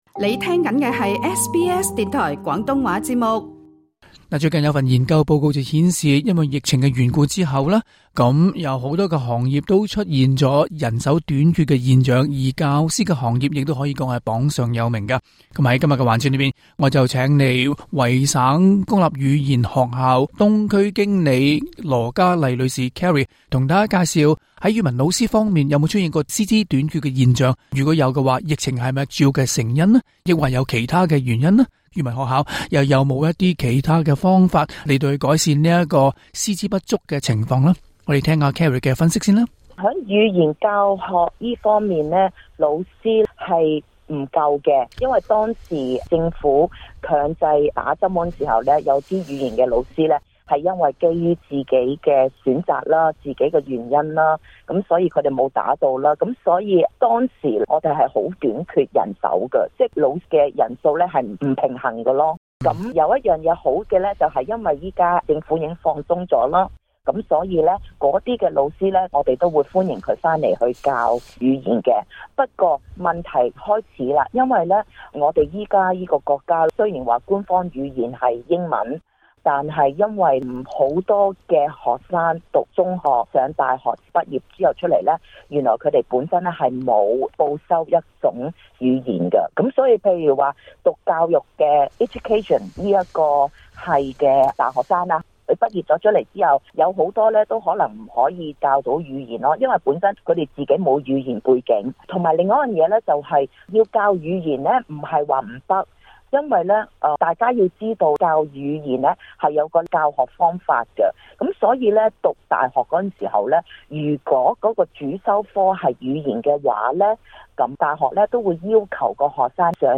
詳情請收聽今期的訪問環節。